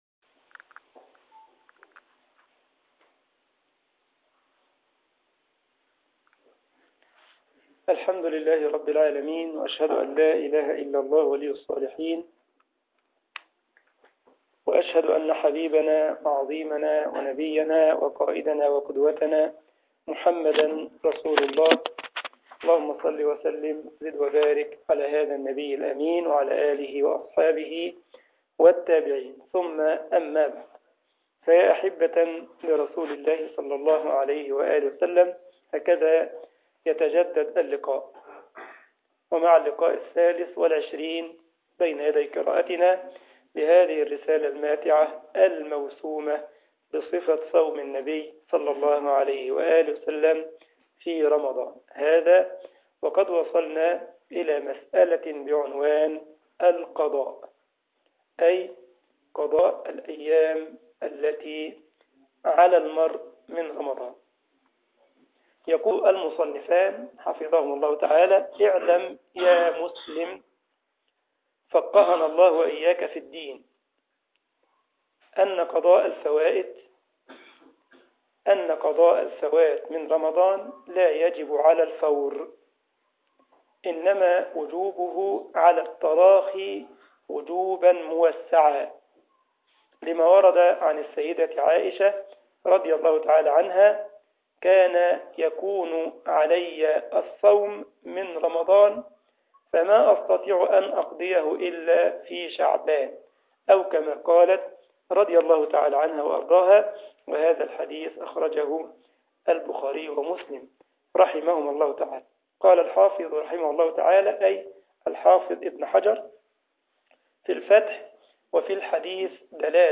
مسجد الجمعية الاسلامية بالسارلند المانيا